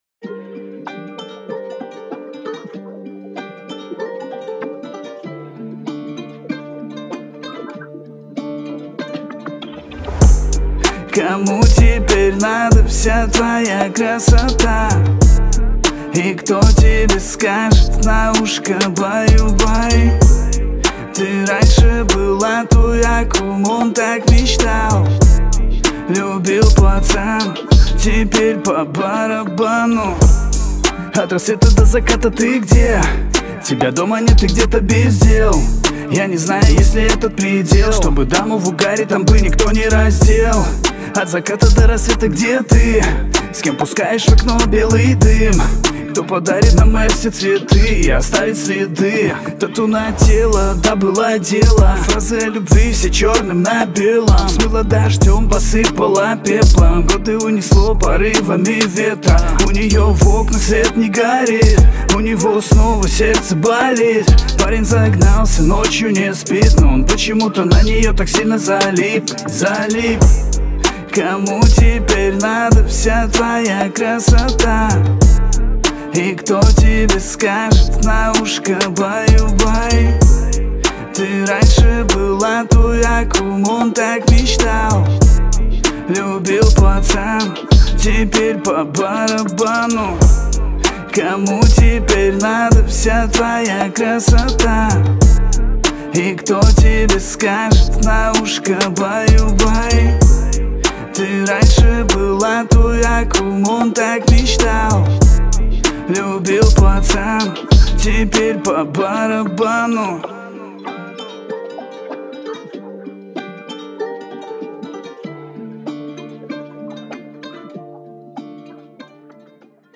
Русские песни
• Качество: 320 kbps, Stereo